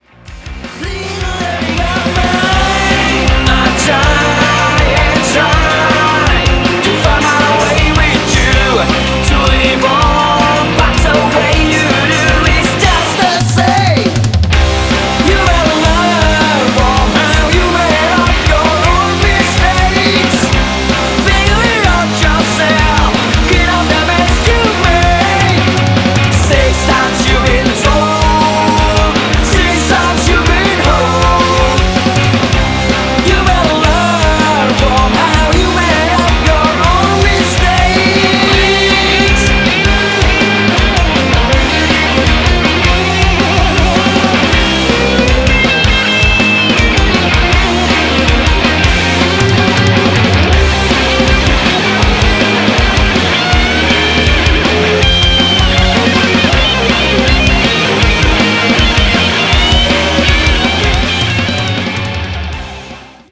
Trio garage rock
drum
vocal-bass
lead guitar
eskplorasi sound yang lebih modern dan alternative